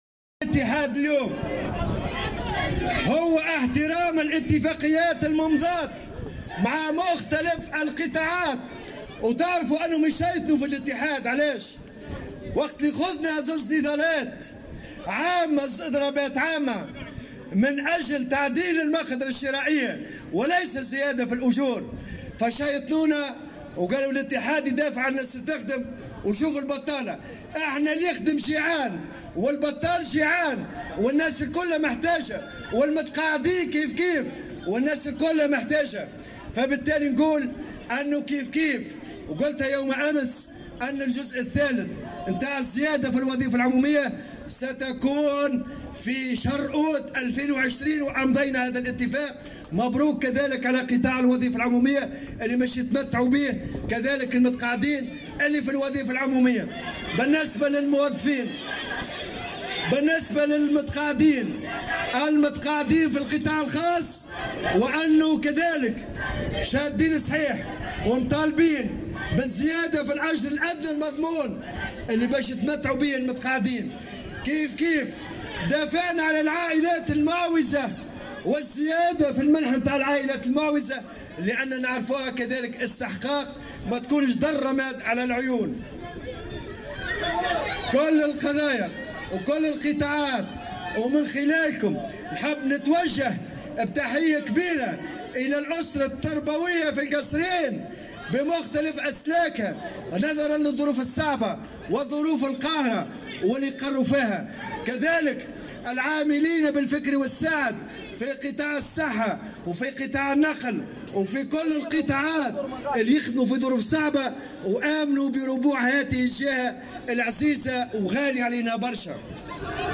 اكد الامين العام للاتحاد العام التونسي للشغل نور الدين الطبوبي صباح اليوم 26 جانفي 2020 خلال اشرافه على احياء الذكرى 74 لتاسيس الاتحاد ان الاتحاد يسعى لتطبيق واحترام كامل الاتفاقيات الممضاة مع مختلف قطاعات الدولة و انه يعمل جاهدا للدفاع عن مطالب كل فئات المجتمع دون تمييز .